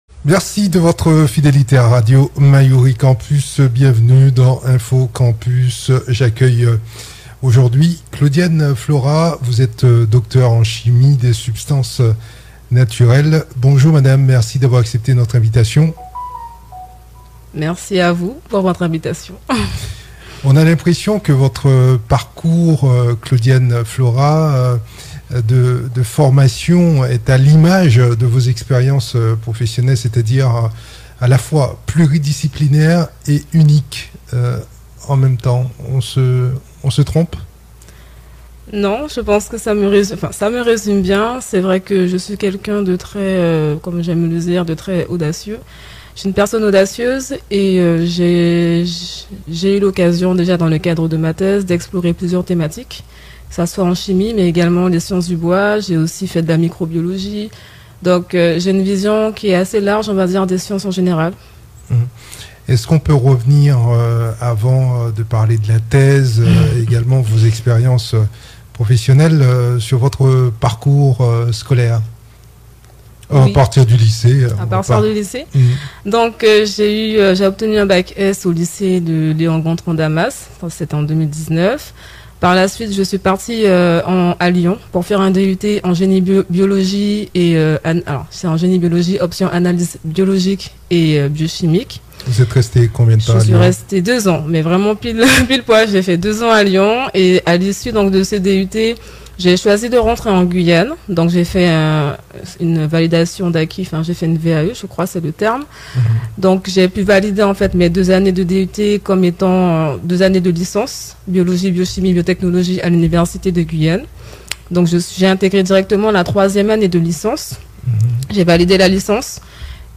Un entretien exclusif, empreint d'humanité, de volonté de changement et d'engagement.